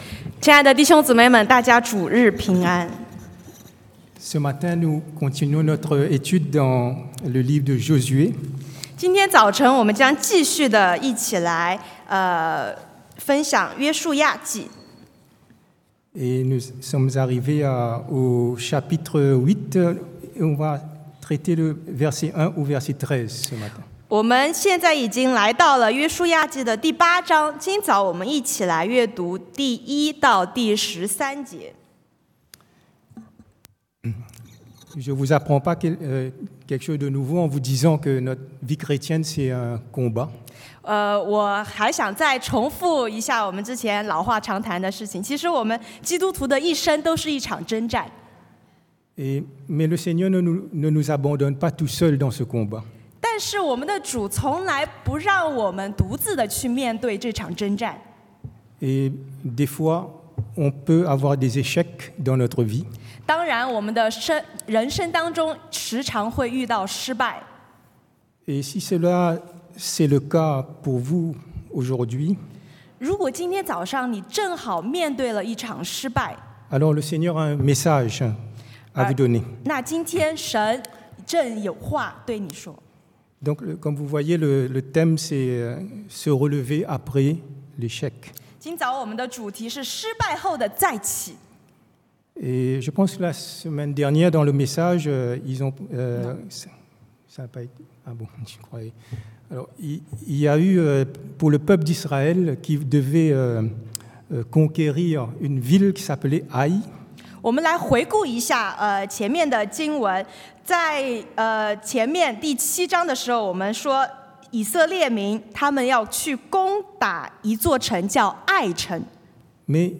Se relever après l’échec 失败后的再起 – Culte du dimanche